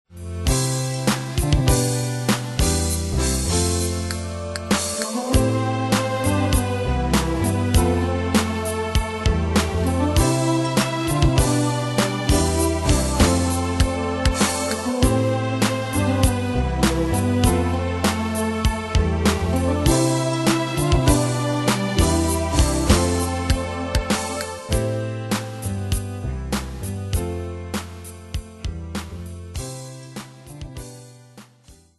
Style: PopFranco Ane/Year: 2006 Tempo: 99 Durée/Time: 4.27
Danse/Dance: Rap Cat Id.
Pro Backing Tracks